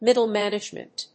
míddle mánagement
音節mìddle mánagement